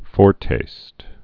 (fôrtāst)